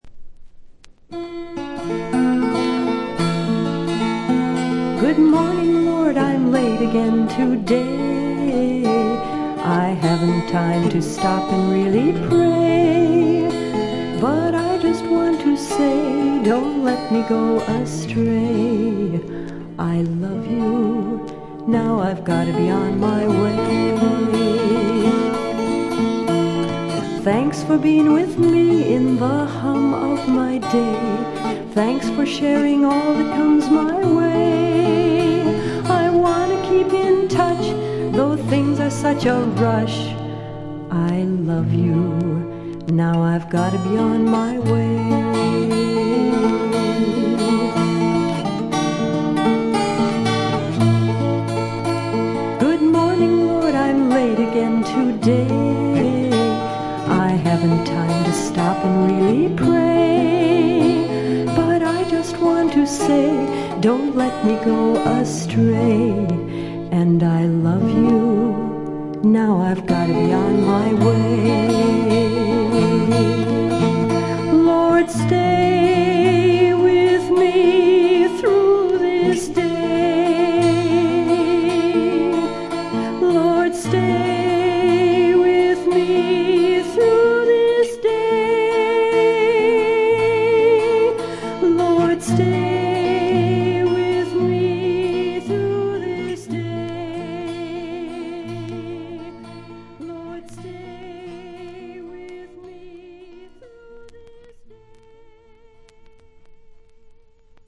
軽いチリプチ程度。
ミネアポリス産クリスチャン・ミュージック／女性シンガーソングライターの佳作。
この時点で一児の母親のようですが、純真無垢な歌声に癒やされます。
試聴曲は現品からの取り込み音源です。
Recorded At - Sound 80 Studios